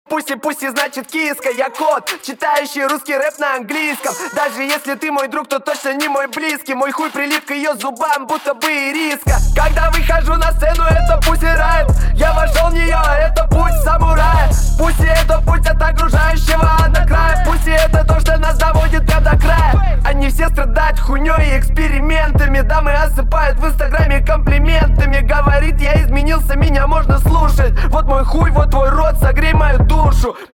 нецензурная лексика
цикличные
Пошлая рэп тема